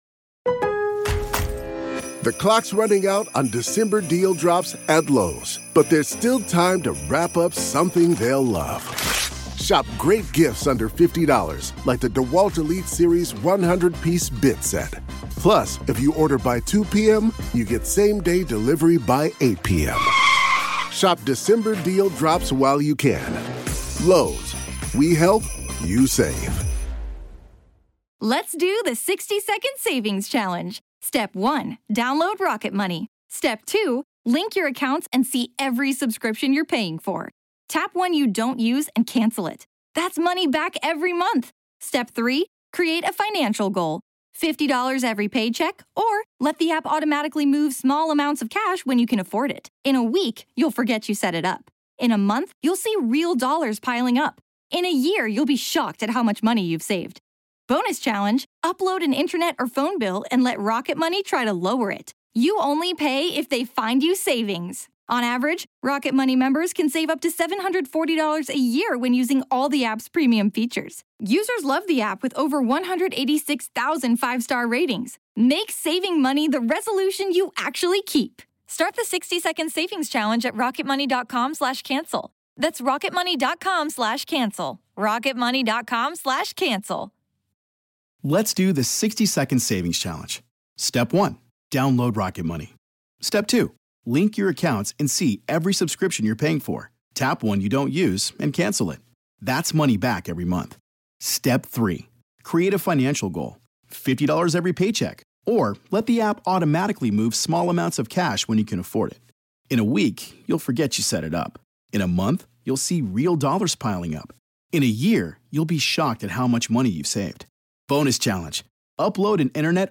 From creaky floorboards to pacing footsteps, the unsettling noises persisted until a visit to a local priest brought a chilling but seemingly effective solution. In this episode, we hear a listener recounting these paranormal events that terrified their family, especially the moments when their unbaptized baby brother seemed to be the focal point.